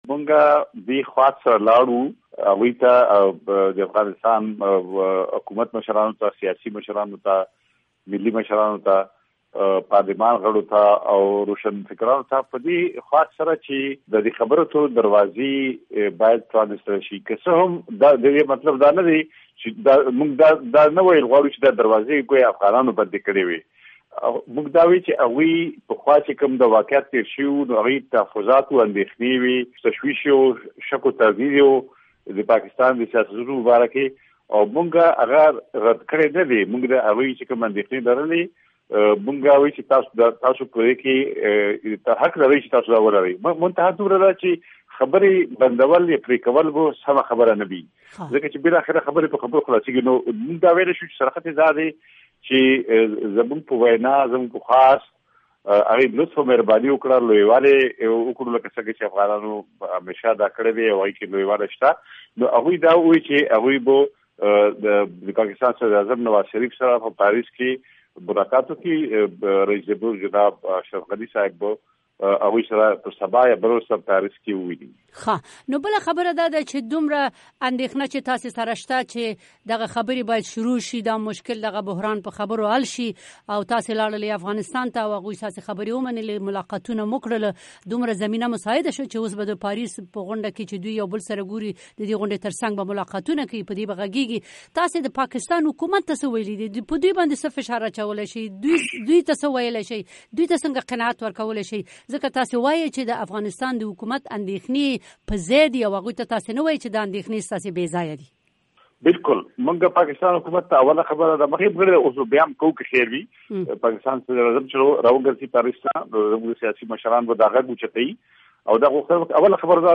افراسیاب خټک سره مرکه